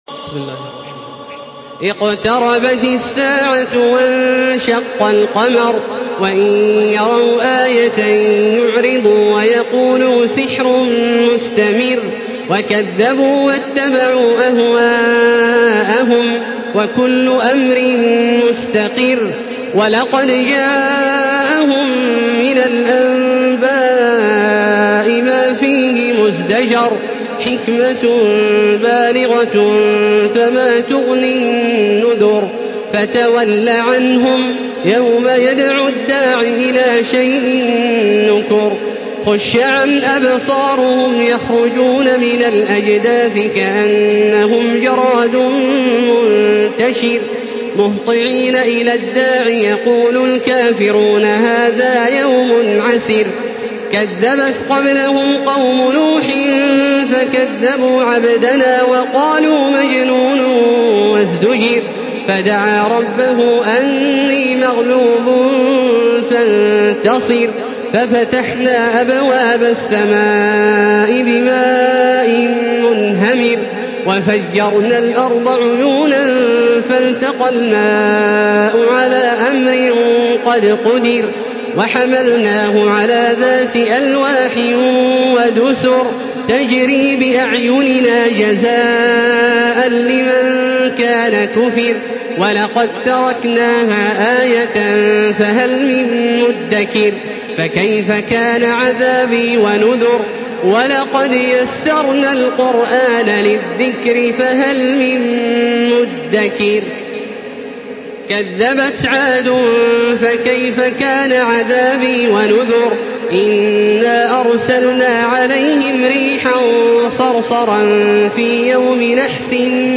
Surah Al-Qamar MP3 in the Voice of Makkah Taraweeh 1435 in Hafs Narration
Listen and download the full recitation in MP3 format via direct and fast links in multiple qualities to your mobile phone.